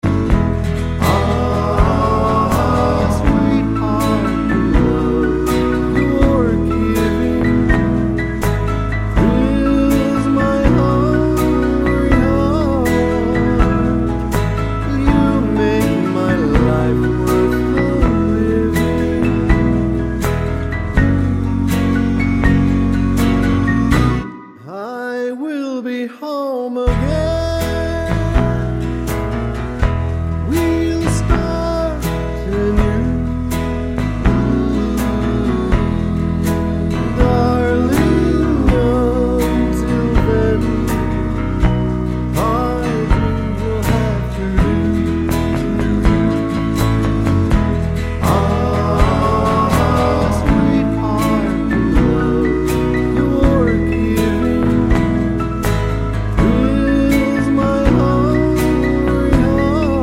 With Harmony Pop (1960s) 2:37 Buy £1.50